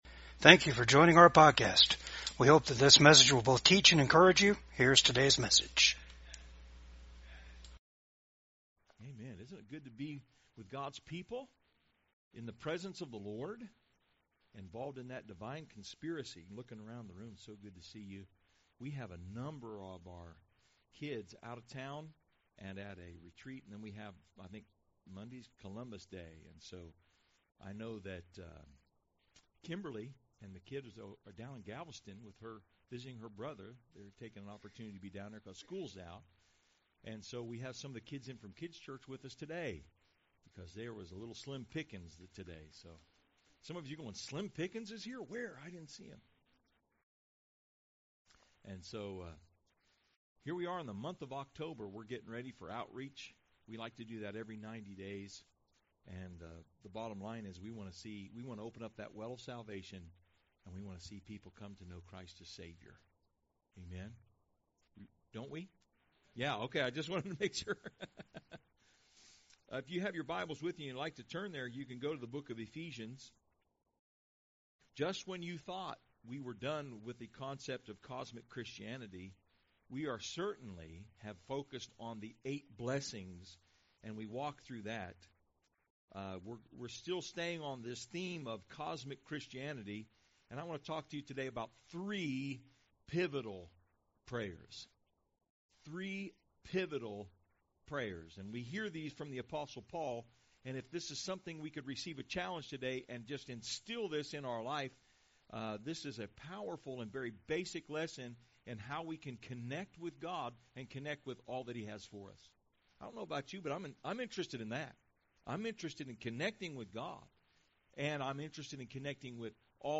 Ephesians 1:15-18 Service Type: VCAG SUNDAY SERVICE 1.